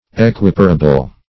Equiparable \E*quip"a*ra*ble\